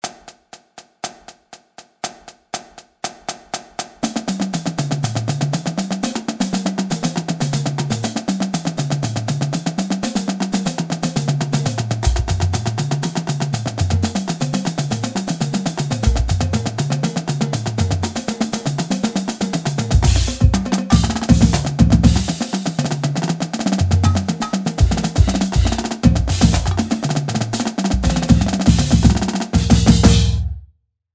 Stick Control hereta - 120.mp3